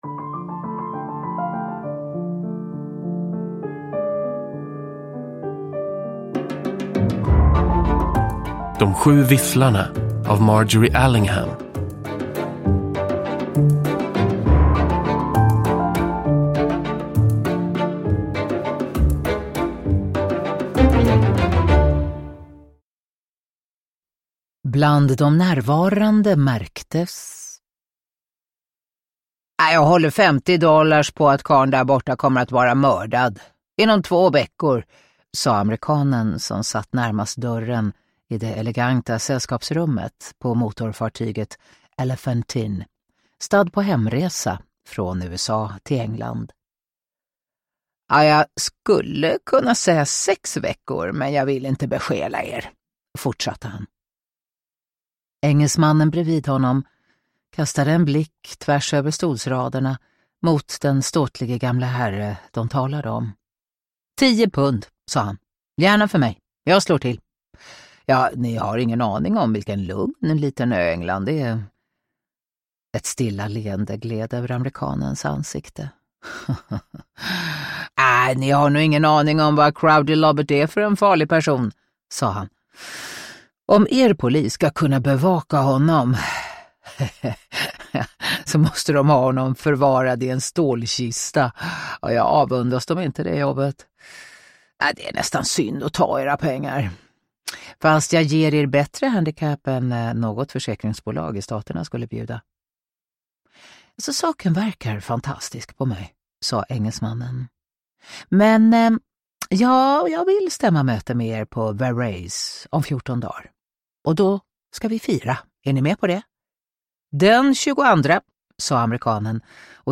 Uppläsare: Katarina Ewerlöf